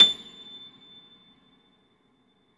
53a-pno25-A5.aif